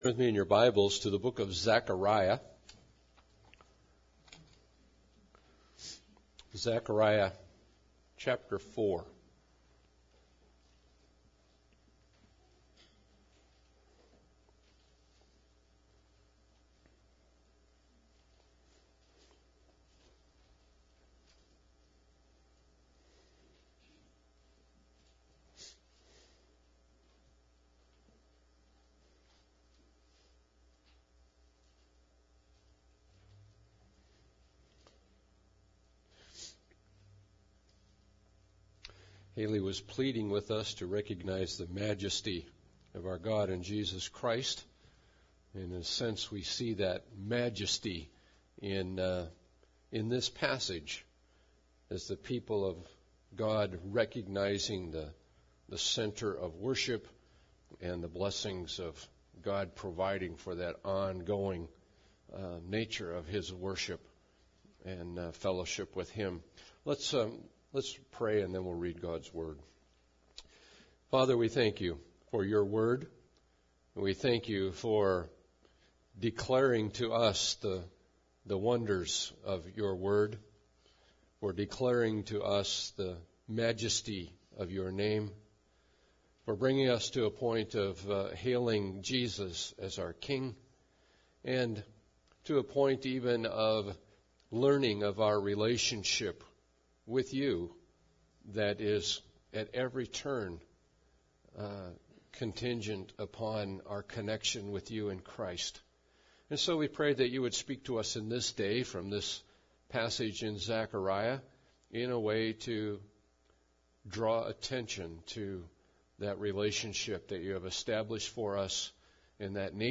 Passage: Zechariah 4 Service Type: Sunday Service